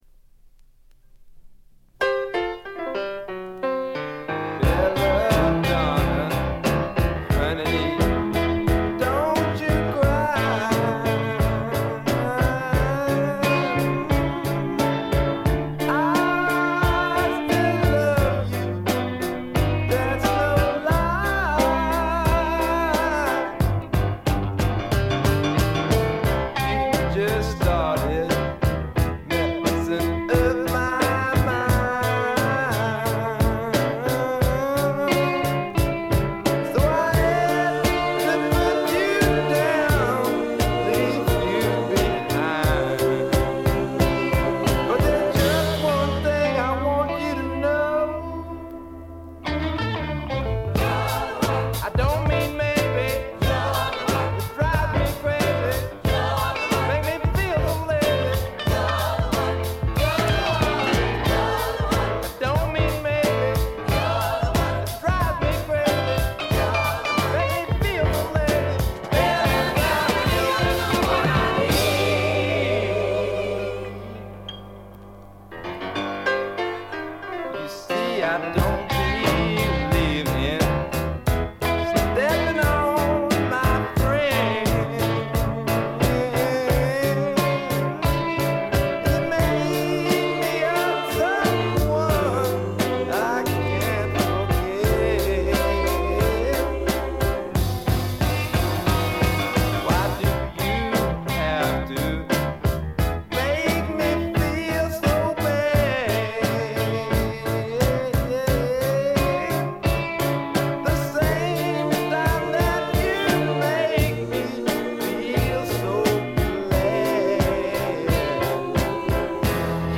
ノイズ感無し。
まさしくスワンプロックの真骨頂。
試聴曲は現品からの取り込み音源です。